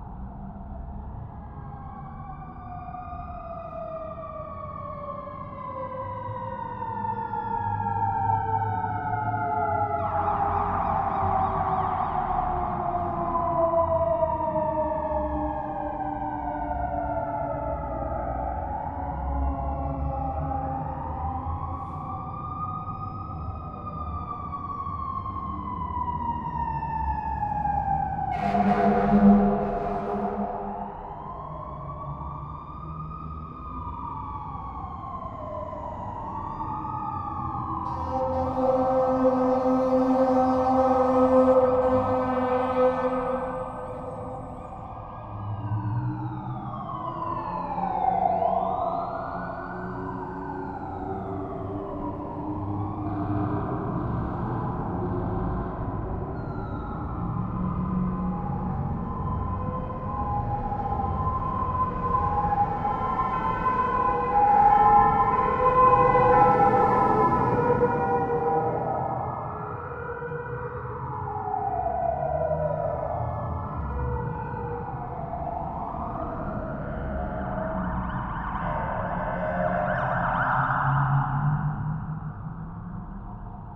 policeSirensReverbLoop.ogg